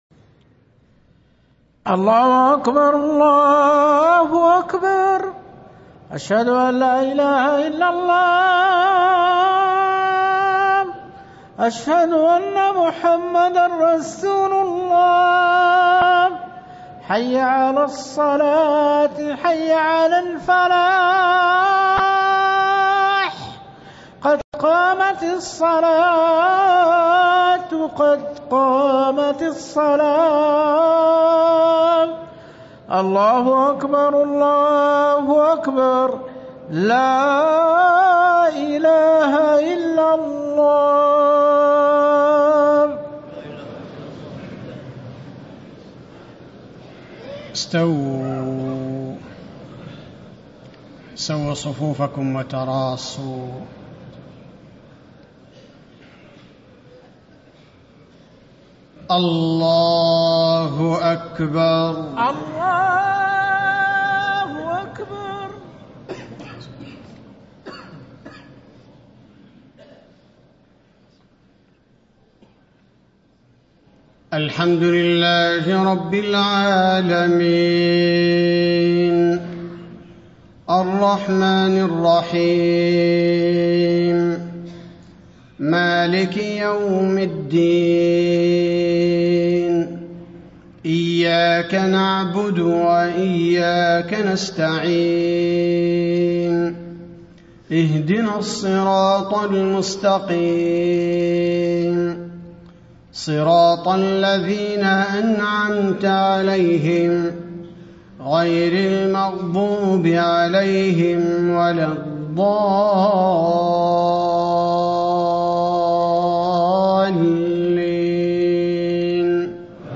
صلاة المغرب 5-4-1435هـ من سورة فصلت > 1435 🕌 > الفروض - تلاوات الحرمين